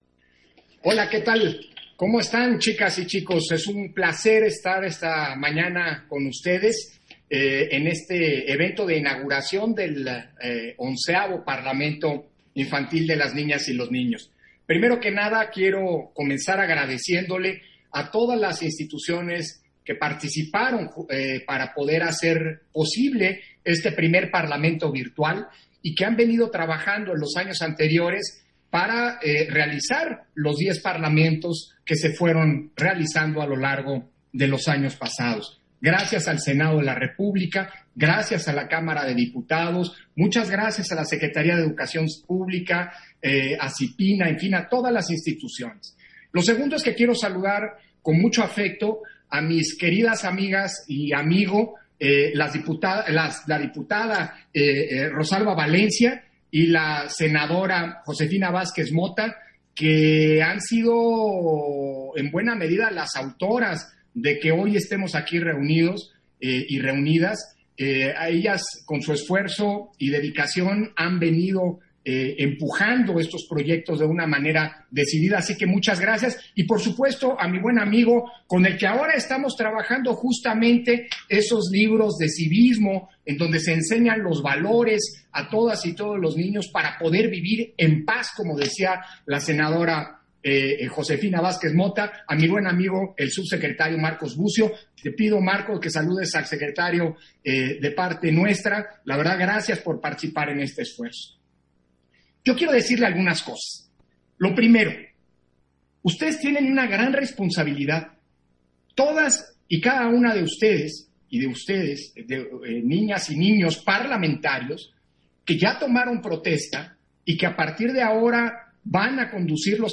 131020_AUDIO_INTERVENCIÓN-CONSEJERO-PDTE.-CÓRDOVA-PARLAMENTO-INFANTIL - Central Electoral